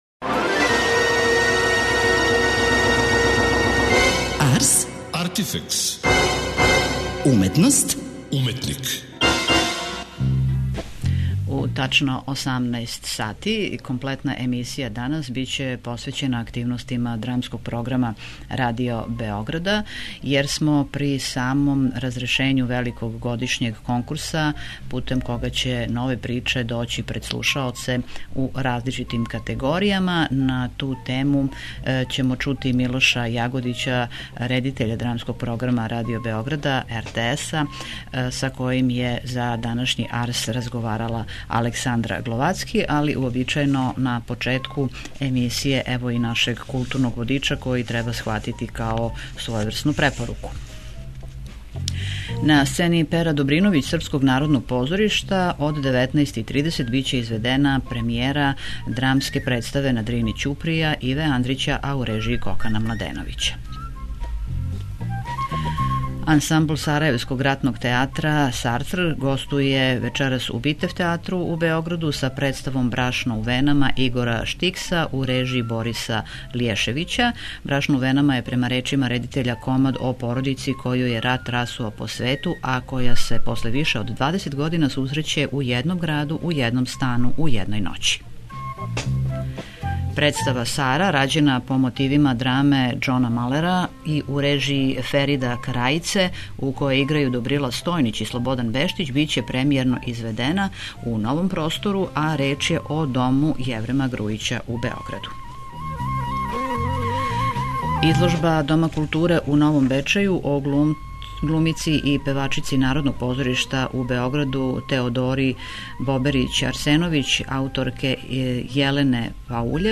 преузми : 27.95 MB Ars, Artifex Autor: Београд 202 Ars, artifex најављује, прати, коментарише ars/уметност и artifex/уметника. Брзо, кратко, критички - да будете у току.